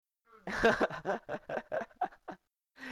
Trippy Laughs